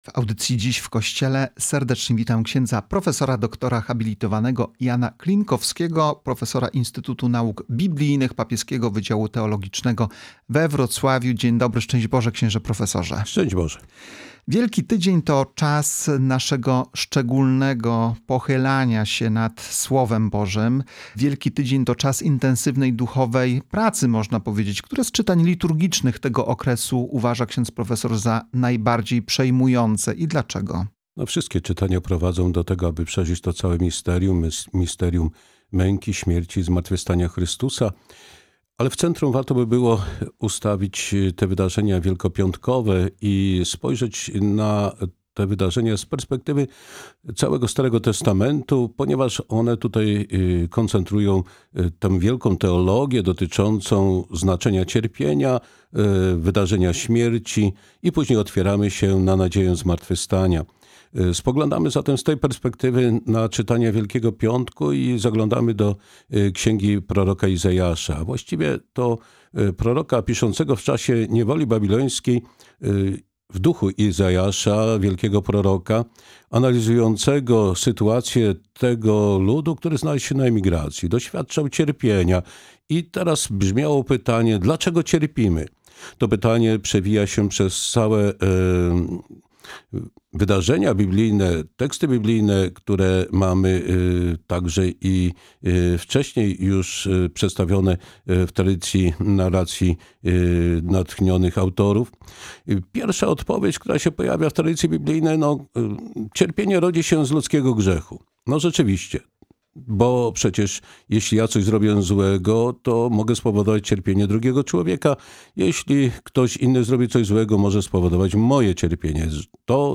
Zapraszamy do wysłuchania całej rozmowy: https